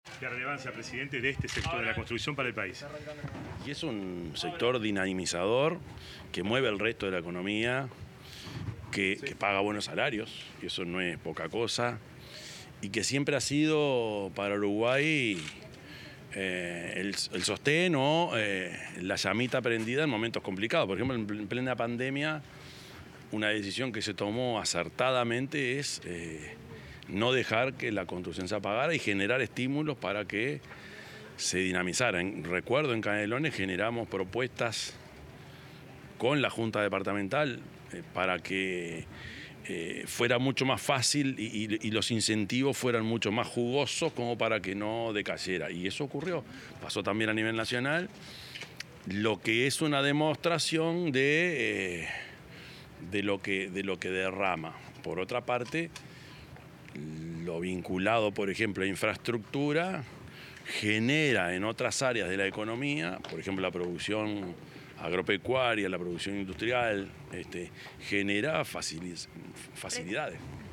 Declaraciones del presidente, Yamandú Orsi, en el Día de la Construcción
Declaraciones del presidente, Yamandú Orsi, en el Día de la Construcción 20/10/2025 Compartir Facebook X Copiar enlace WhatsApp LinkedIn El presidente de la República, Yamandú Orsi, realizó declaraciones a los medios informativos tras participar en una actividad conmemorativa del Día de la Construcción, que se realizó en el Club de Golf, en Montevideo.